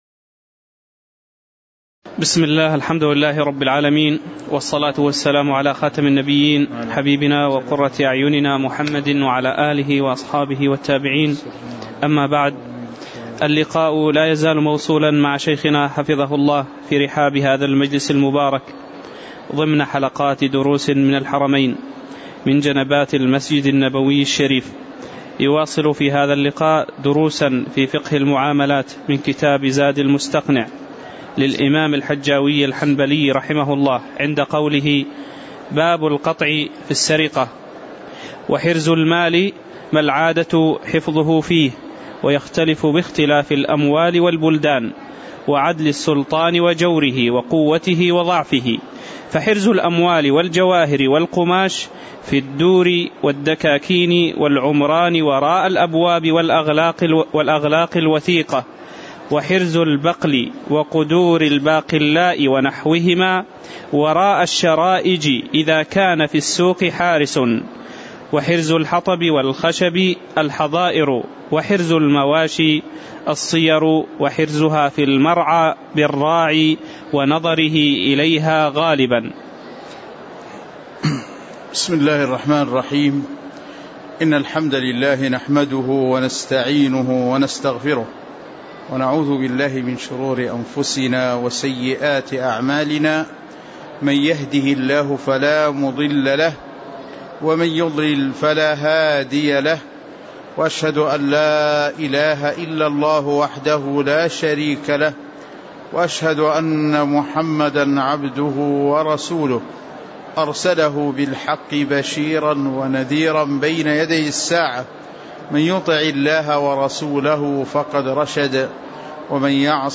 تاريخ النشر ٢٤ جمادى الأولى ١٤٣٨ هـ المكان: المسجد النبوي الشيخ